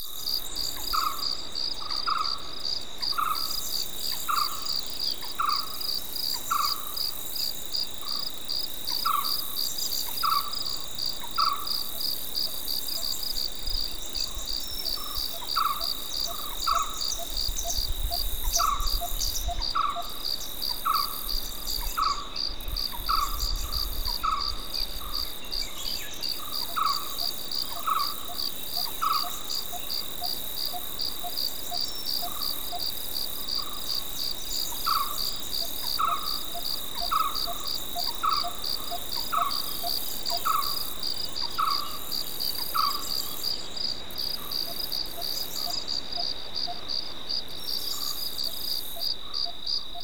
day_warm_climate.ogg